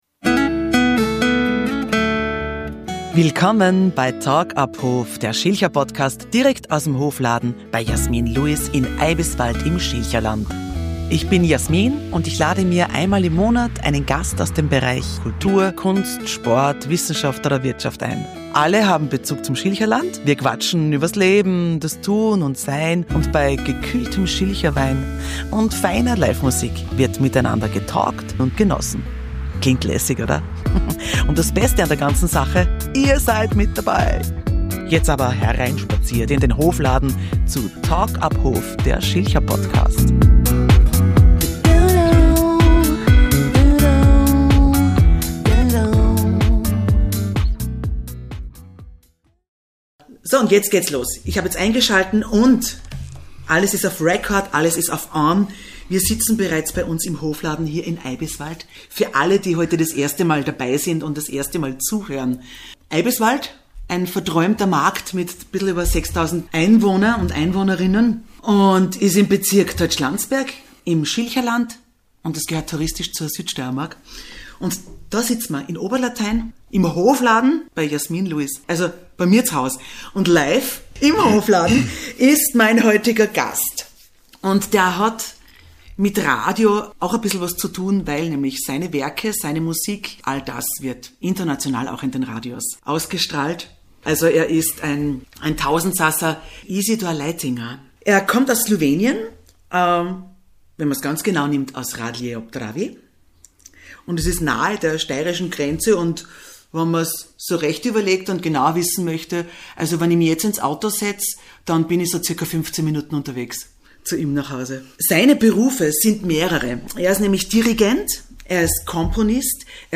Im Gespräch geht es um seinen Weg als Musiker, Komponist und Dirigent, seine internationalen Stationen und Projekte und das ARSfest Festival in Radlje ob Dravi. Ihr könnt erleben wie Atemübungen live im Podcast klingen